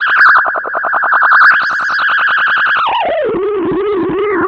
Filtered Feedback 14.wav